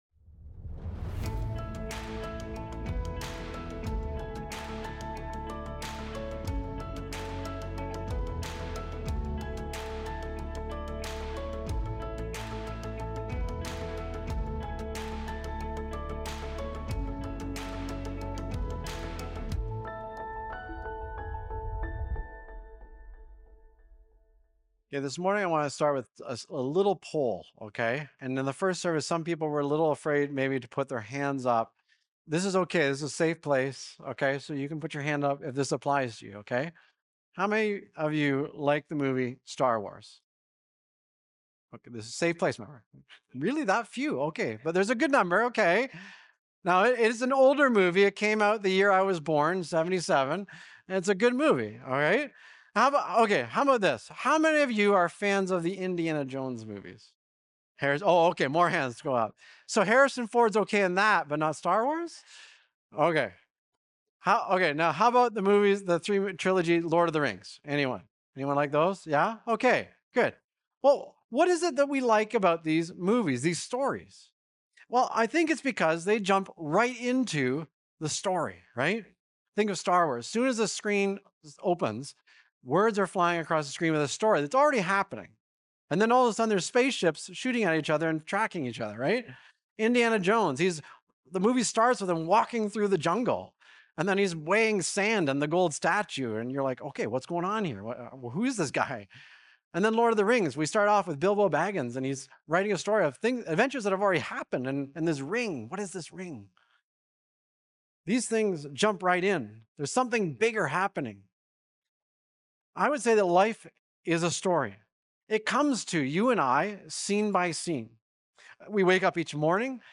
Recorded Sunday, February 1, 2026, at Trentside Fenelon Falls.